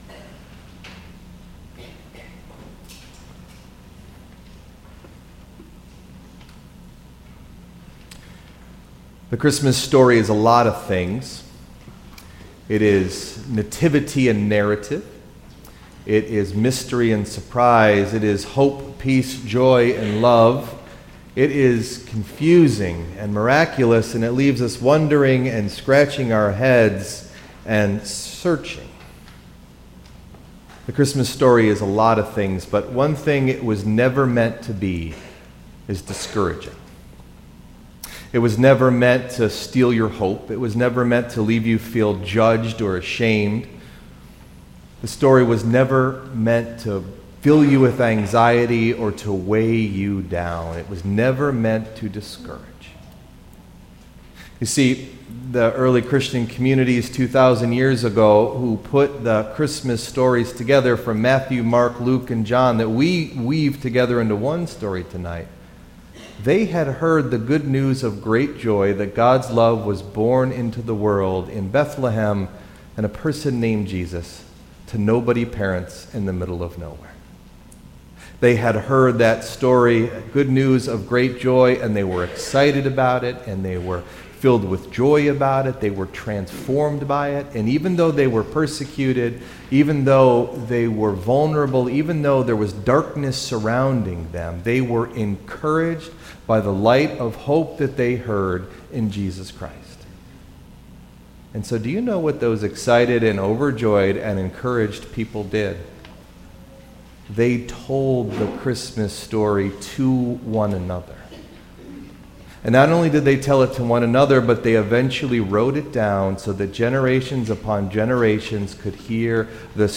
Date: December 24th, 2019 (Christmas Eve – 7pm service)
Message Delivered at: Charlotte Congregational Church (UCC)